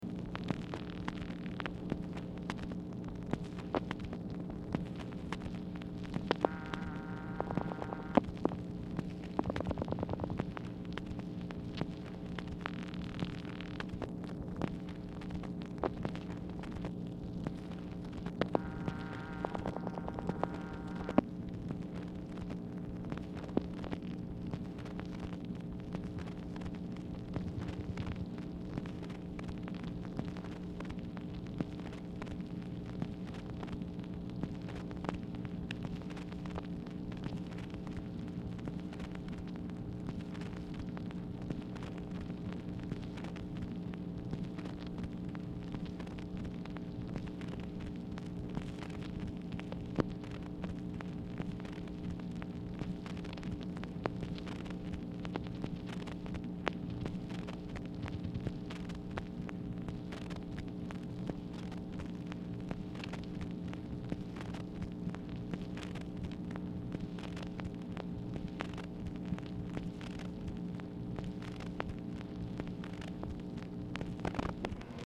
Telephone conversation # 5055, sound recording, MACHINE NOISE, 8/20/1964, time unknown | Discover LBJ
Format Dictation belt